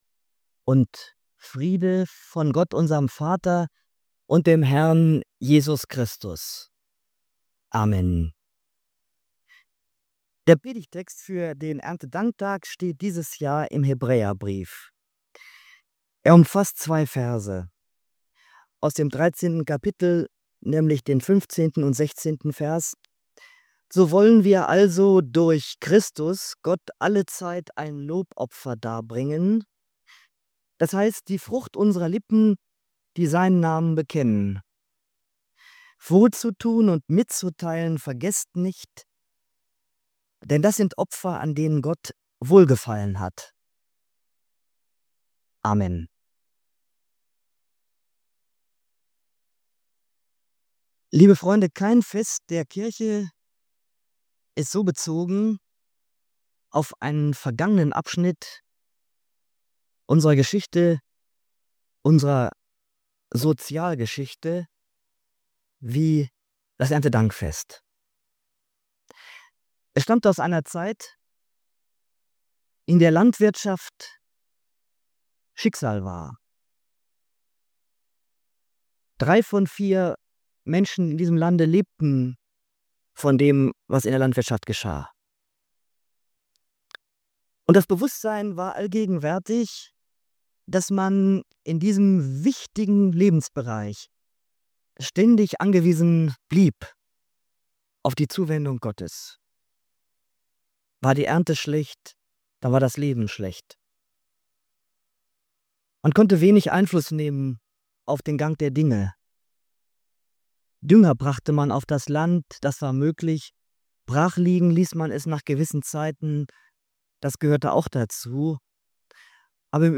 Wozu brauchen wir da noch das Erntedankfest? In dieser Predigt geht es um das vergessene Grundgesetz des Lebens: Wir sind Beschenkte.